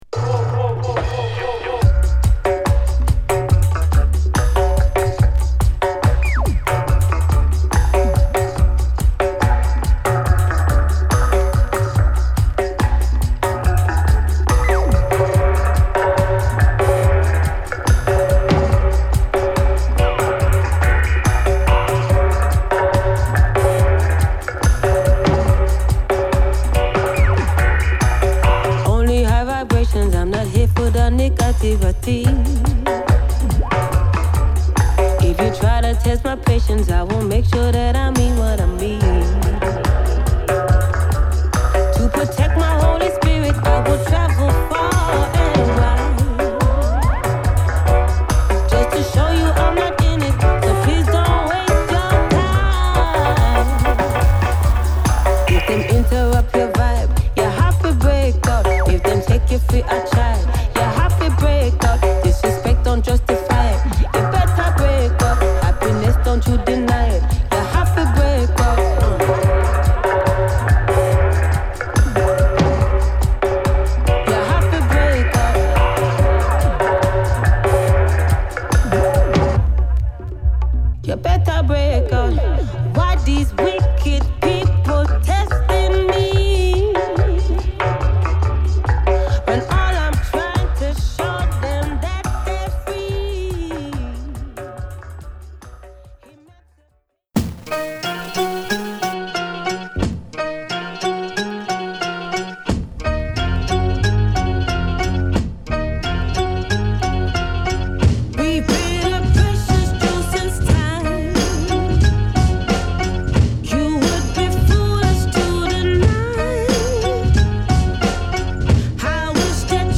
ダビーなルーツレゲエとラックに乗ったA1
重厚なHip Hop／サンプリングトラックでのA2
伸びやかなネオソウルA3